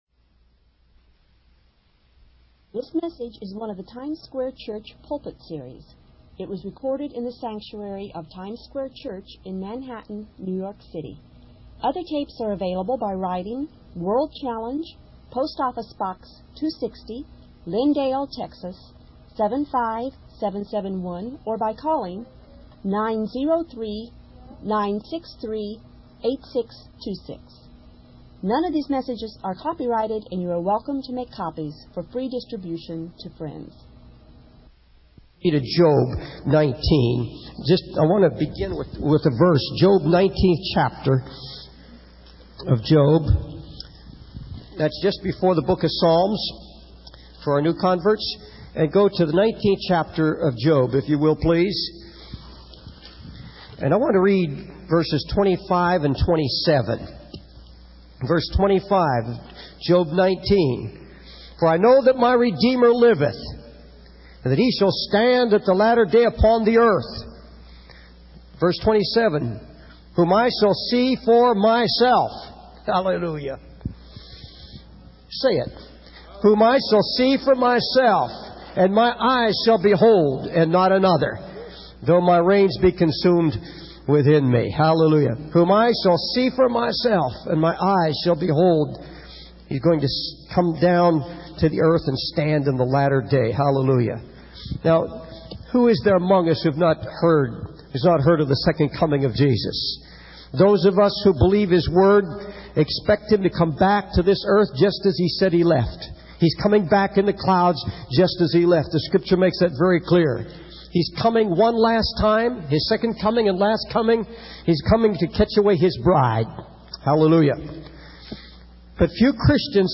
In this sermon, the preacher outlines several end time events that he believes will happen soon. The first event is that Jesus will bring an abrupt end to time and the judgments will be finished.
It was recorded in the sanctuary of Times Square Church in Manhattan, New York City.